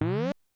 menu_close.wav